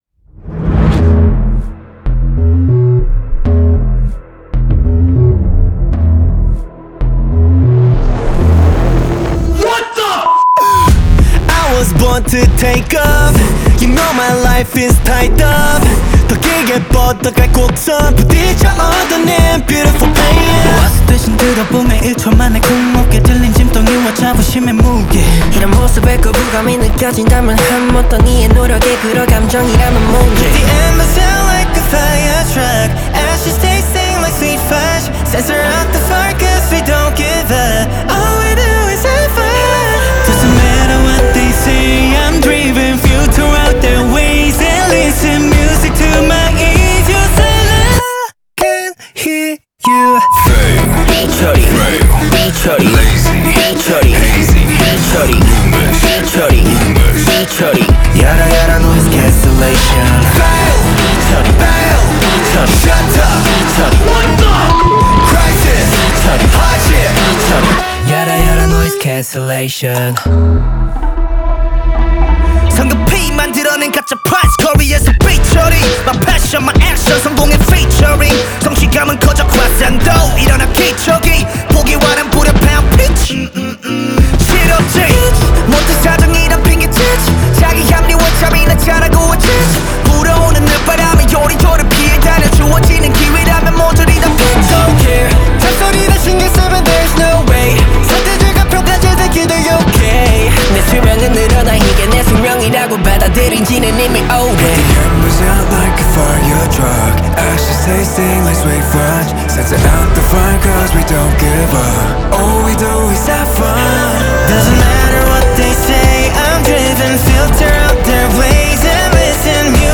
Ps：在线试听为压缩音质节选，体验无损音质请下载完整版
K-POP